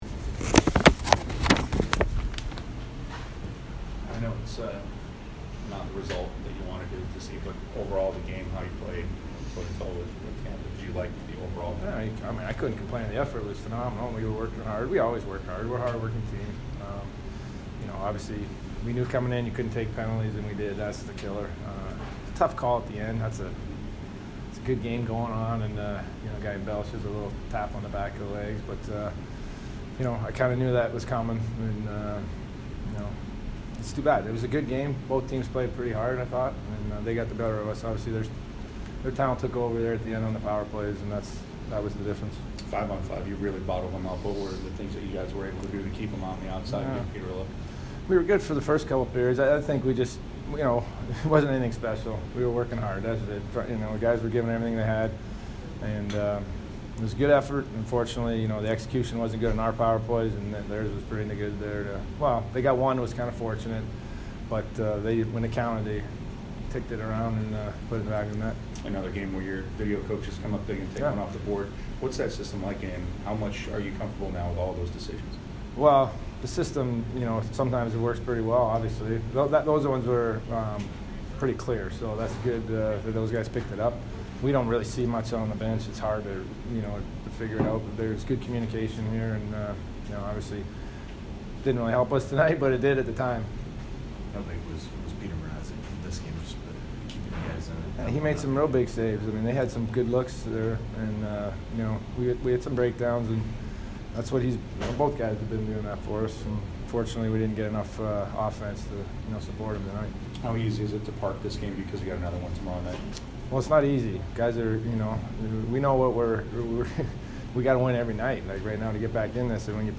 Rod Brind'Amour post-game 1/10